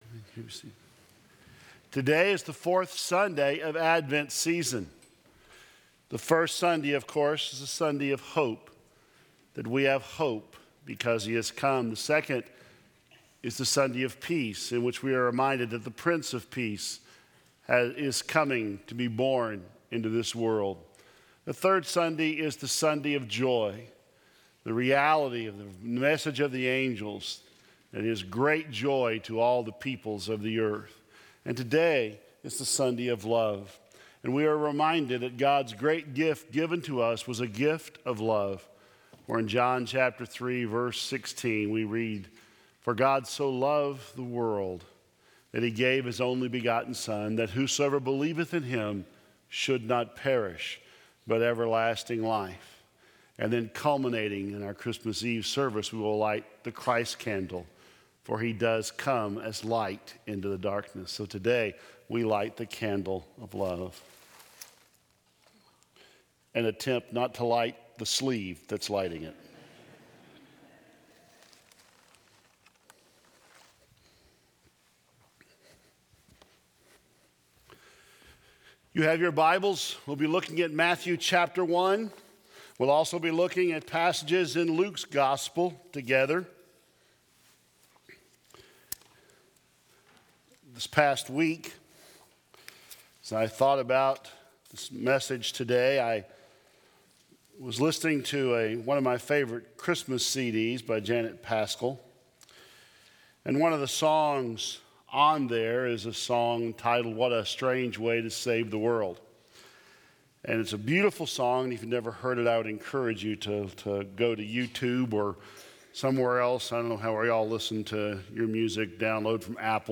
2015 Related Share this sermon